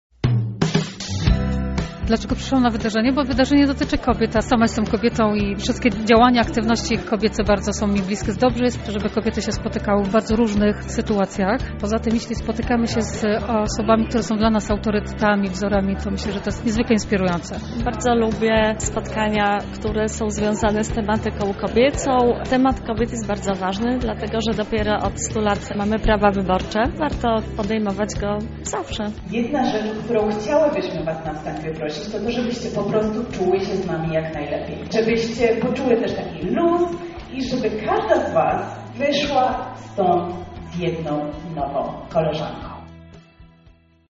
Uczestniczki powiedziały nam, dlaczego wzięły udział w wydarzeniu.